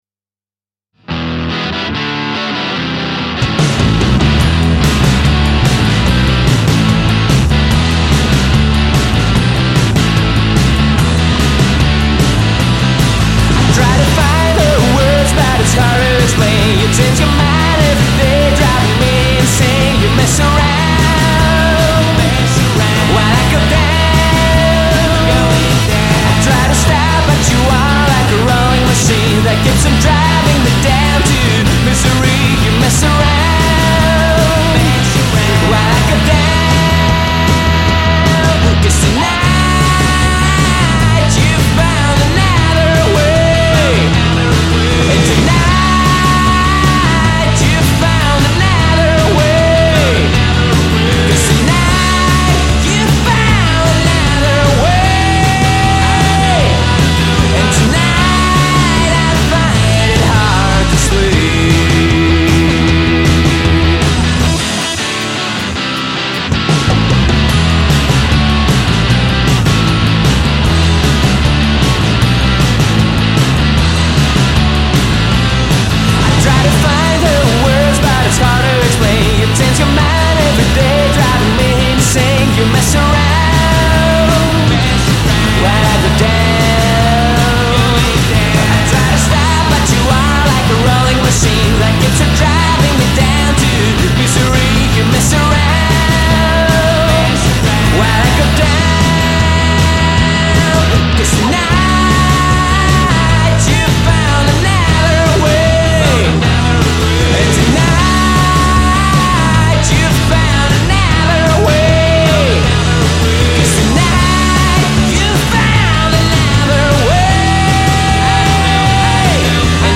in 2016 and although being a garage rock band at its core
such as surf and noise rock.